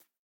rabbit_hop4.ogg